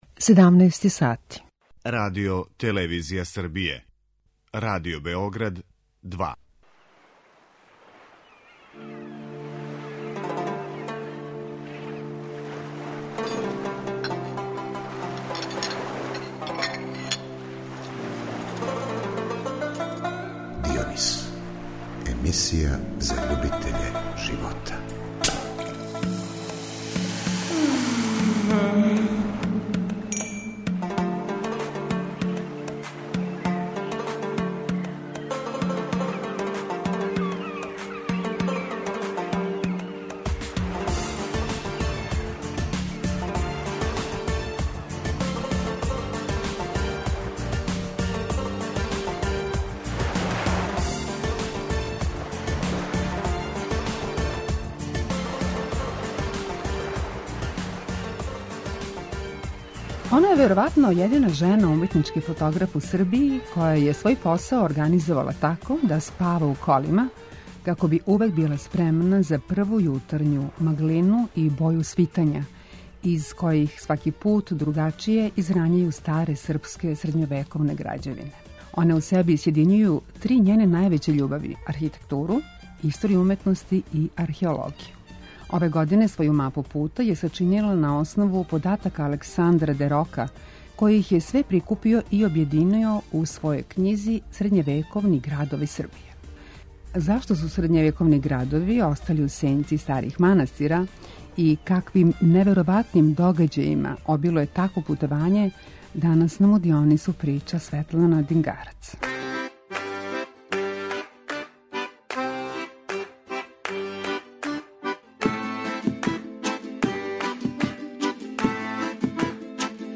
Емисија за љубитеље живота